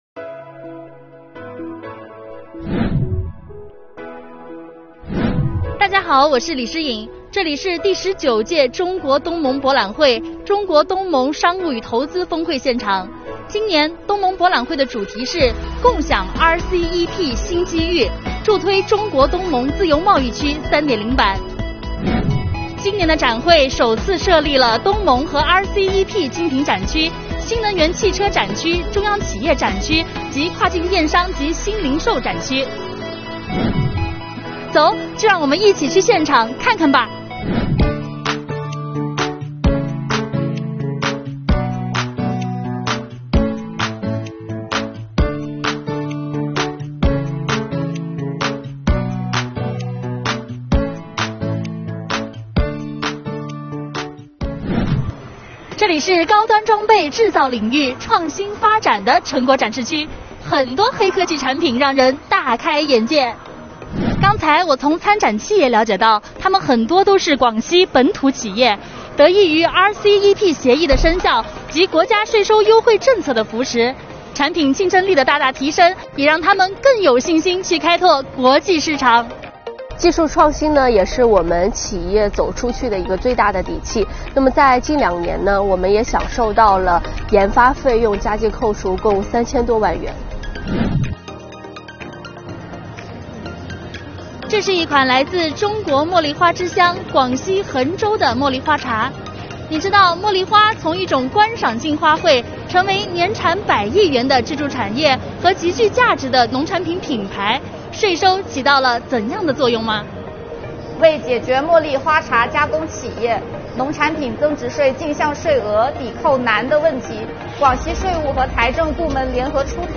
第19届中国—东盟博览会和中国—东盟商务与投资峰会正在广西南宁举行，此次博览会已有267个投资合作项目签约，总投资4130亿元，比上届增长37%，10亿元以上项目有87个。
东博会常办常新，每年都有特色和亮点，今年的“东博会”有啥亮点？税务小姐姐带你去逛逛↑↑↑